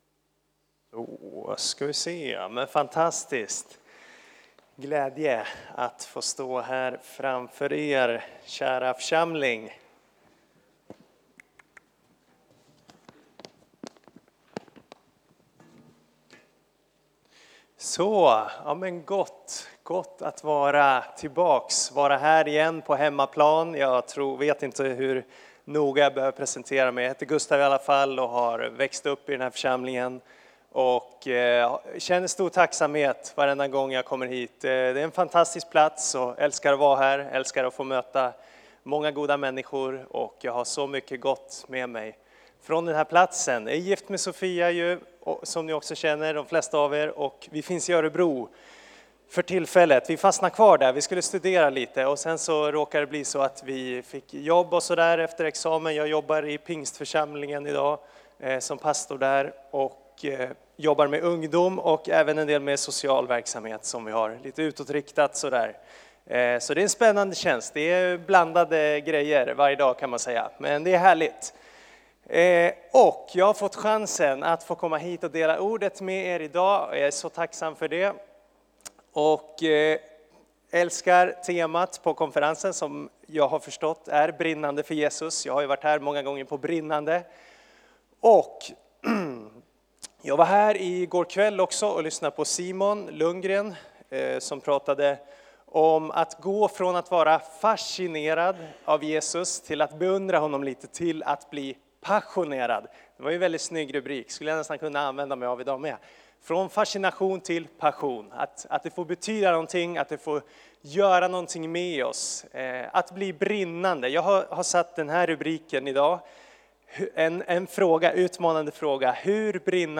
Externa talare